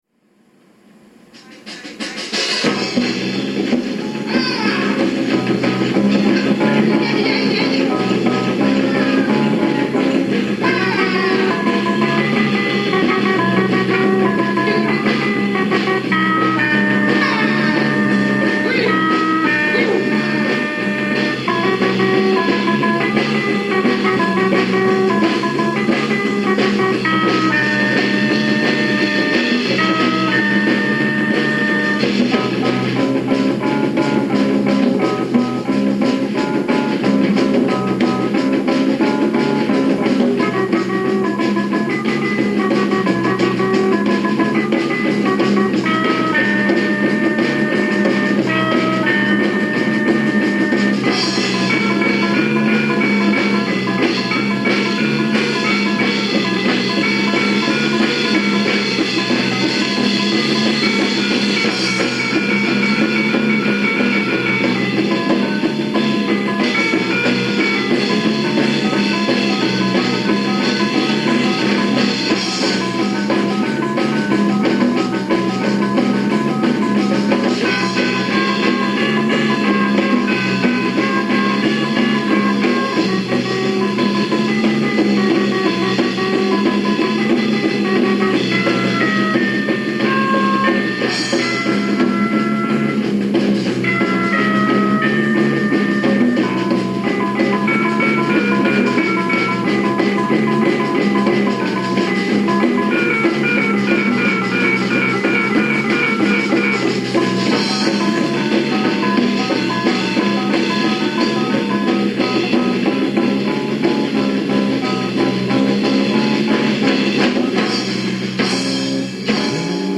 Filmmusik